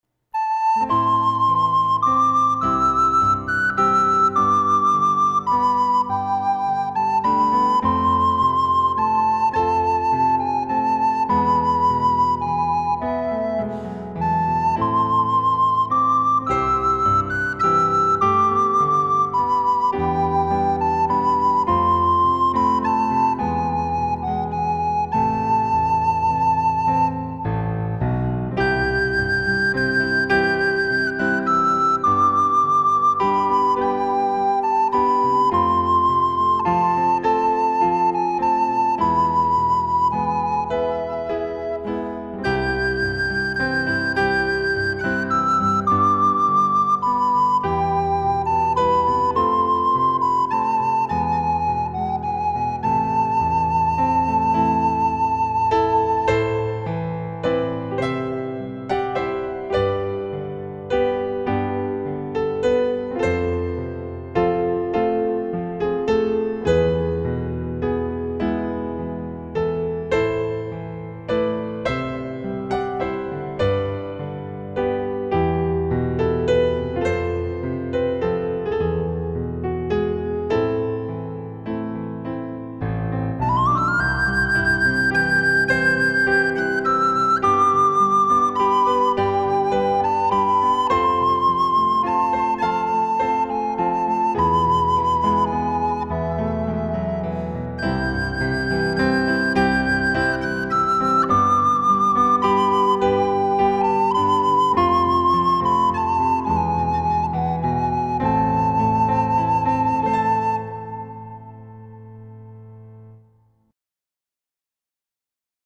۲. فایل صوتی MP3 اجرای مرجع
• اجرای مرجع با تمپوی استاندارد و بیان احساسی صحیح